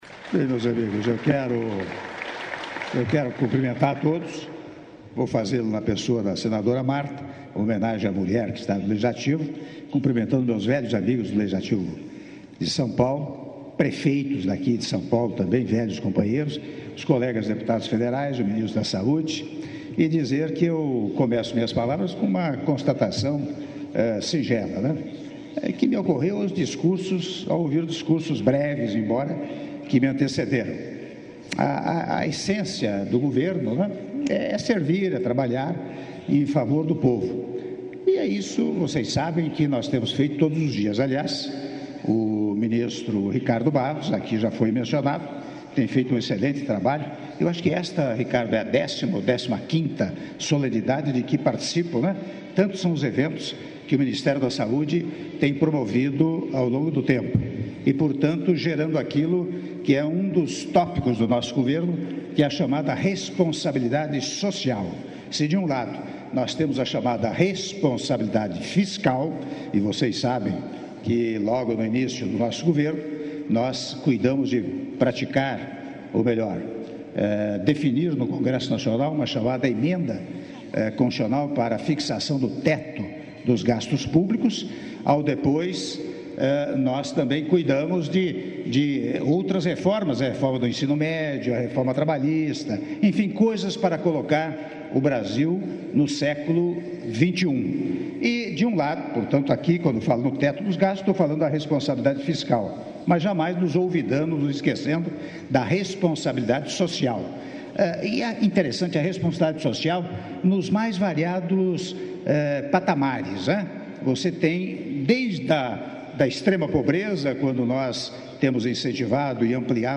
Áudio do discurso do Presidente da República, Michel Temer, durante cerimônia de entrega de ambulâncias para renovação da frota do SAMU - São Paulo/SP (07min51s)